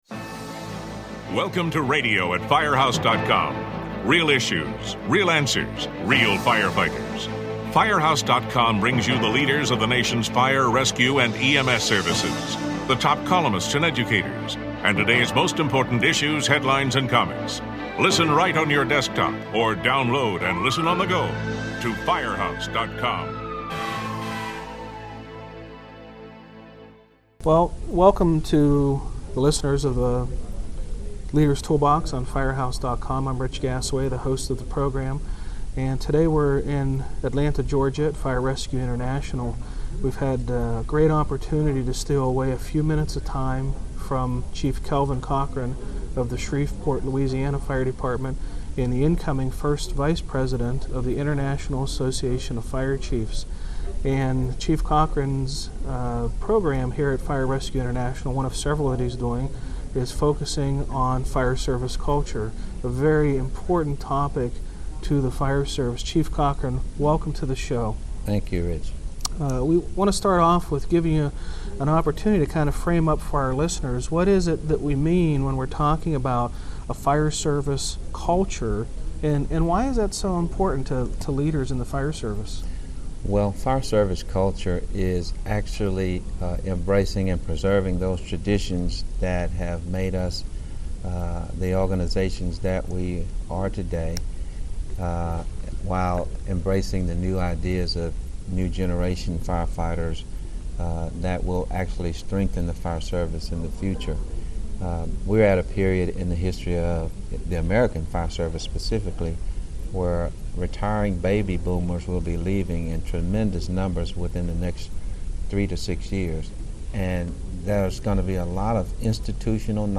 an interview with Shreveport Fire Chief Kelvin Cochran that was conducted at Fire-Rescue International